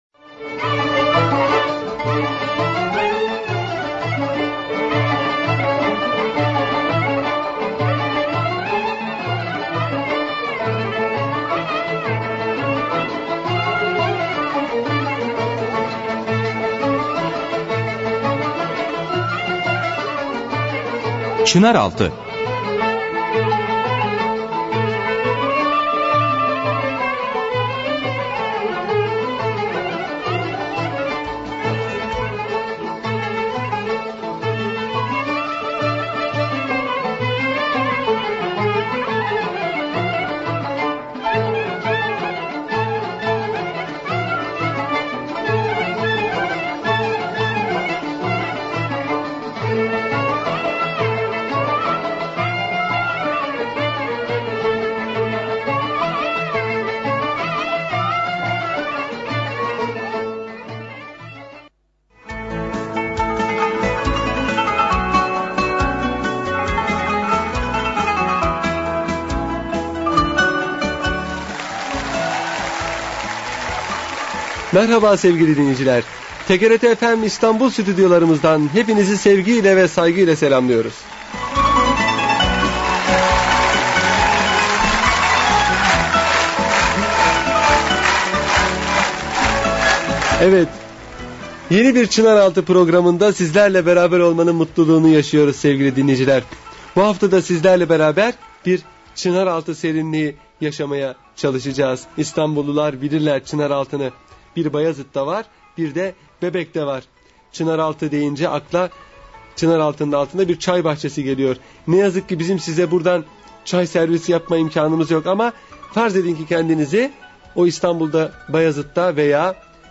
Radyo Programi - Galata Semti